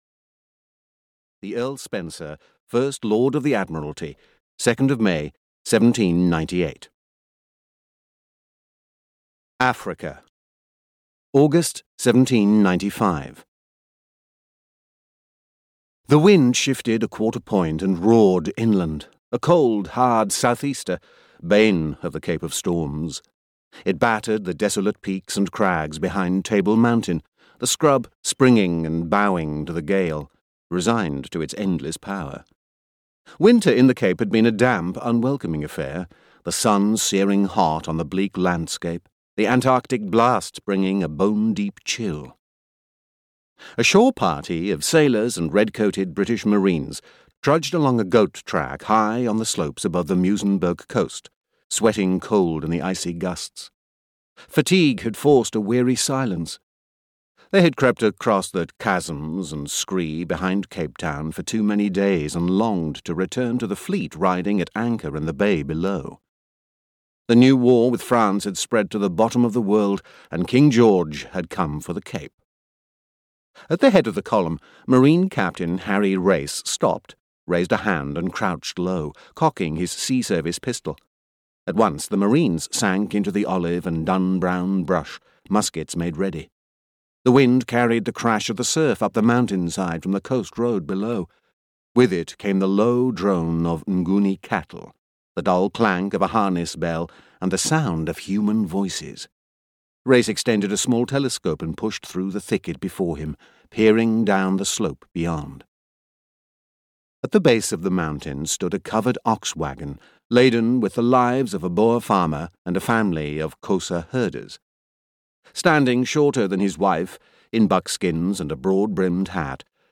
Napoleon's Run (EN) audiokniha
Ukázka z knihy